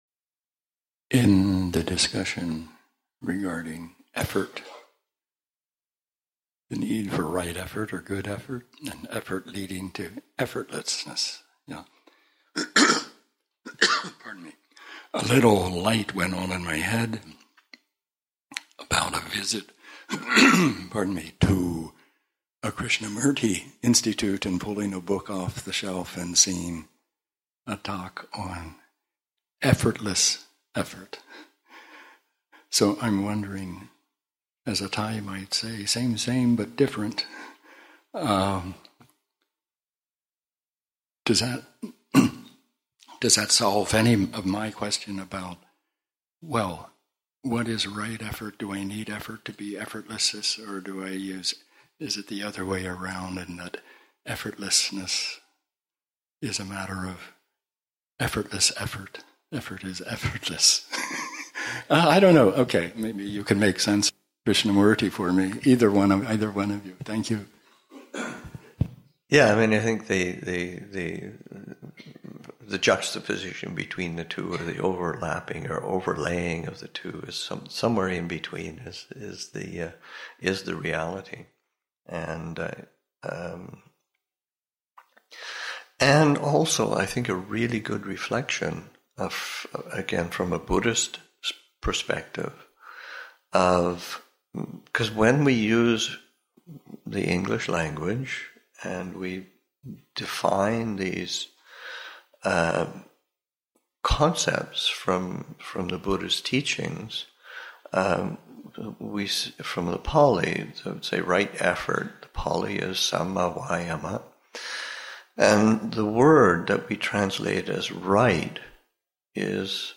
Interreligious Retreat-Seminar on Dhamma and Non-duality, Session 2 – Nov. 25, 2023